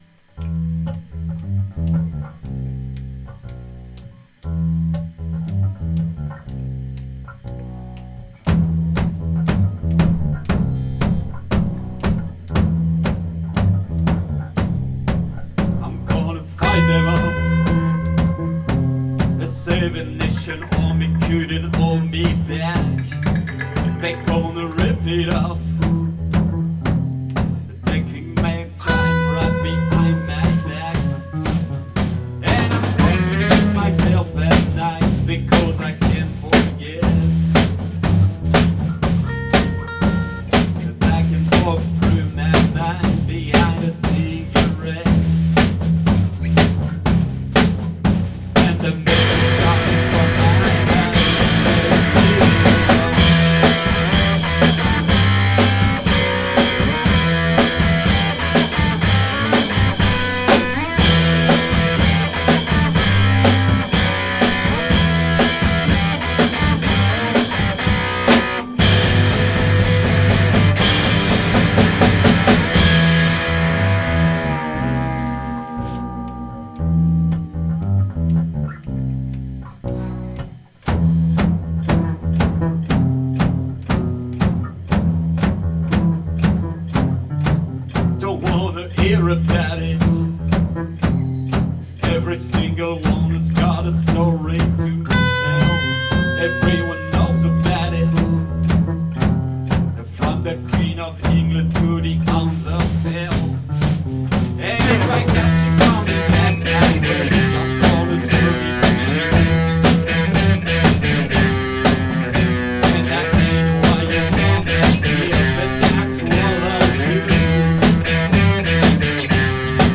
en répète au Studio 58 Télécharger le MP3